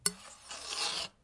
描述：记录的刀片声音。
Tag: 刀片声 单击 打击乐器 录音 毛刺 叶片 振动 现场录音 拍摄 声音